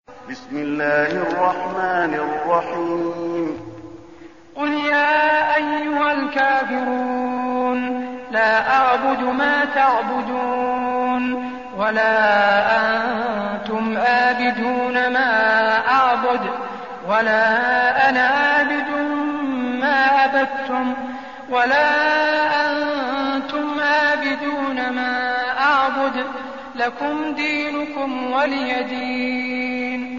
المكان: المسجد النبوي الكافرون